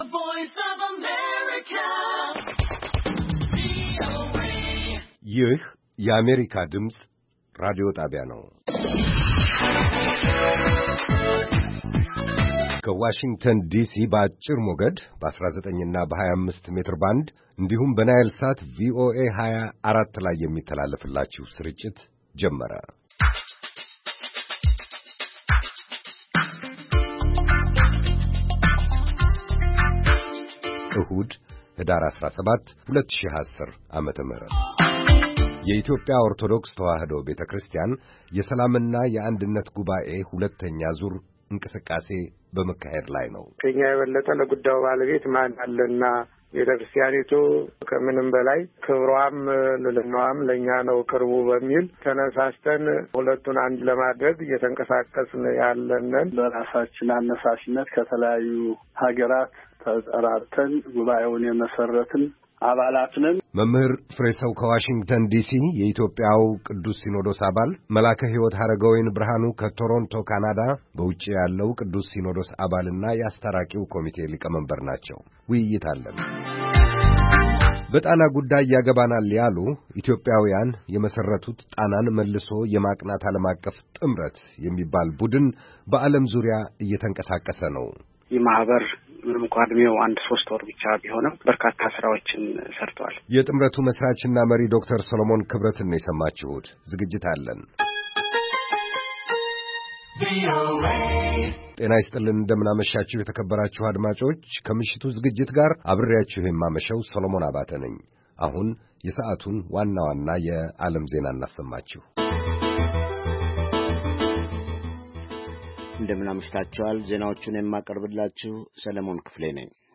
ቪኦኤ በየዕለቱ ከምሽቱ 3 ሰዓት በኢትዮጵያ አቆጣጠር ጀምሮ በአማርኛ፣ በአጭር ሞገድ 22፣ 25 እና 31 ሜትር ባንድ የ60 ደቂቃ ሥርጭቱ ዜና፣ አበይት ዜናዎች ትንታኔና ሌሎችም ወቅታዊ መረጃዎችን የያዙ ፕሮግራሞች ያስተላልፋል። ዕሁድ፡- ራዲዮ መፅሔት፣ መስተዋት (የወጣቶች ፕሮግራም) - ሁለቱ ዝግጅቶች በየሣምንቱ ይፈራረቃሉ፡፡